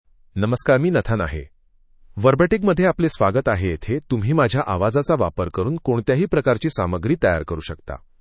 Nathan — Male Marathi AI voice
Nathan is a male AI voice for Marathi (India).
Voice sample
Listen to Nathan's male Marathi voice.
Nathan delivers clear pronunciation with authentic India Marathi intonation, making your content sound professionally produced.